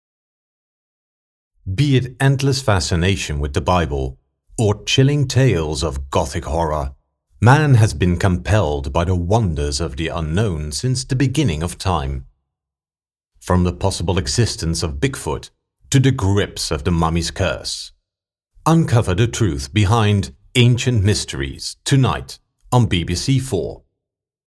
Bilingual (Dutch and English) voice actor
Trailer - UK English
Middle Aged
Ancient Mysteries - UK English VO sample - trailer.mp3